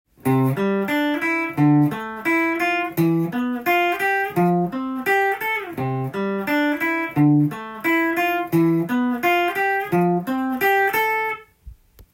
指がなまらないギターフレーズ集TAB譜
譜面通り弾いてみました